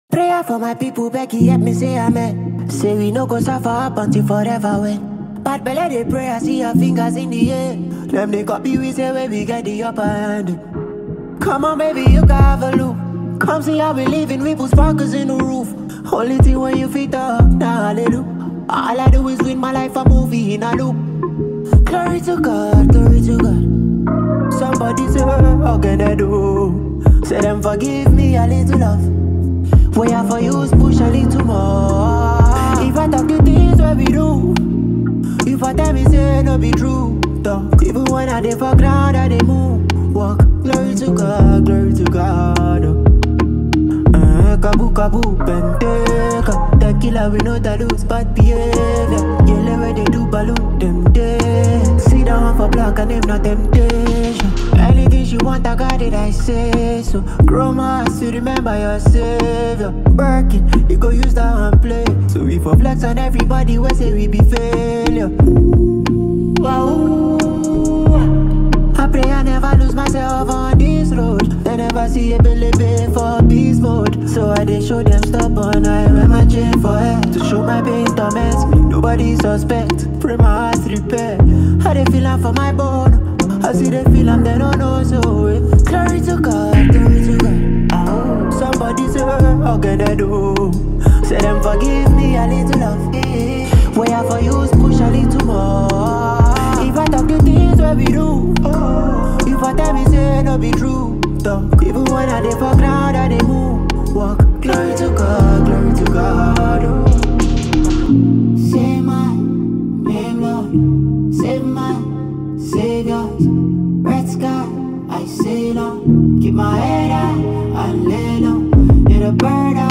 soul-stirring anthem